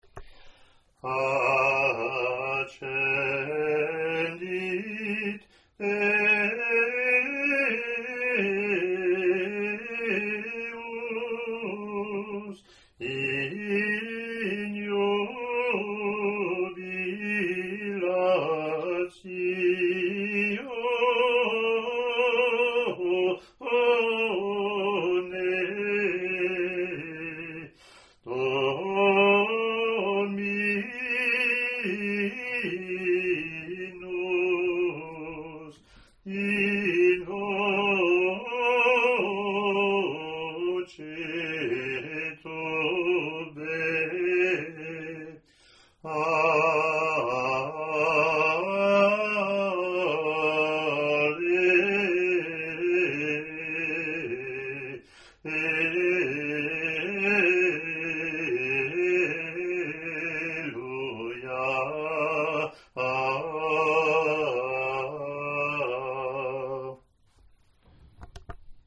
eaal-offertory-gm.mp3